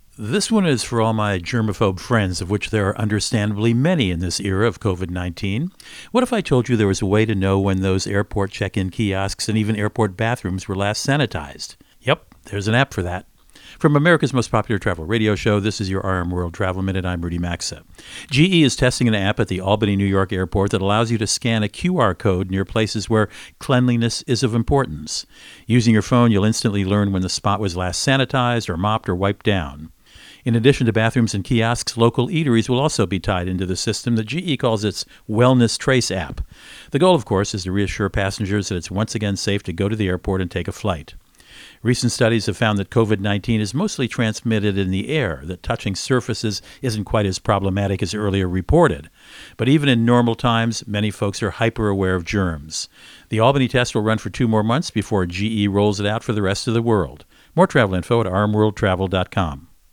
America's #1 Travel Radio Show
Monday, 30 Nov 20 .. Co-Host Rudy Maxa | How Clean is That Airport Bathroom?